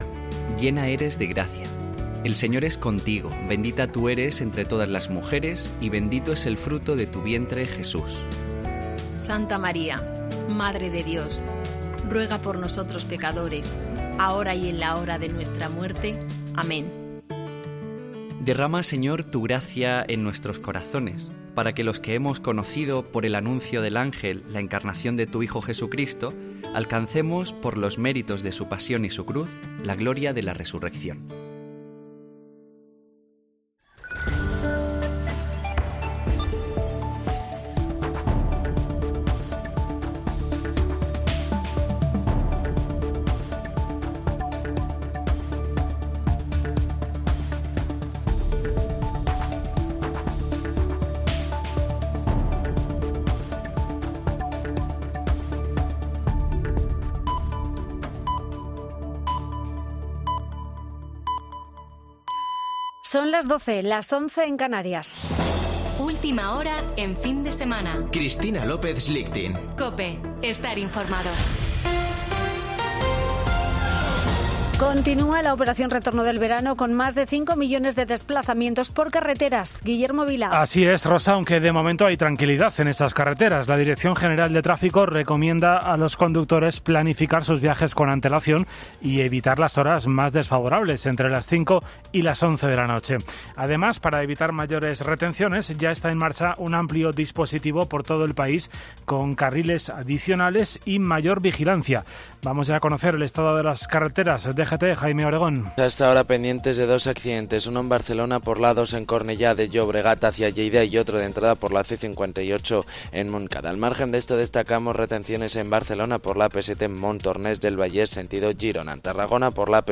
Boletín de noticias de COPE del 27 de agosto de 2022 a las 12.00 horas